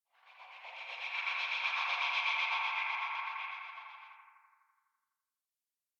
Soft Air Passby 01.wav